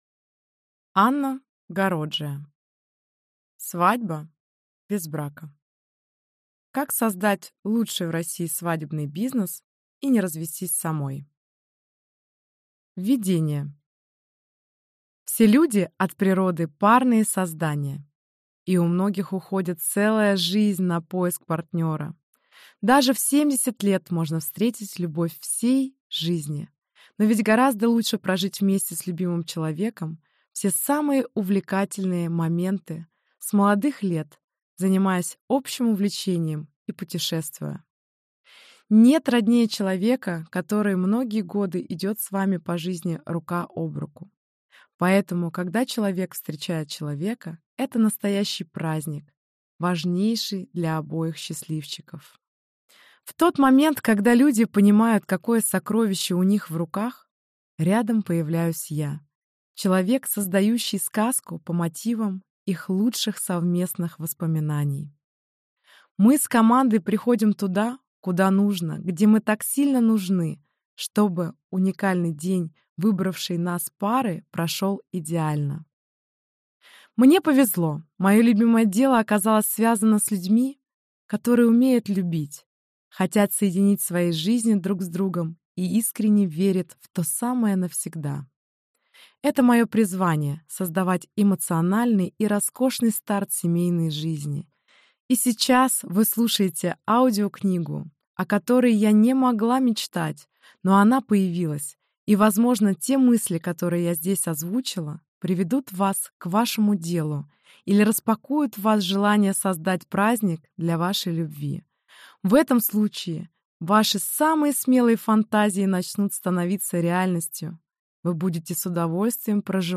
Аудиокнига Свадьба без брака. Как создать лучший в России свадебный бизнес и не развестись самой | Библиотека аудиокниг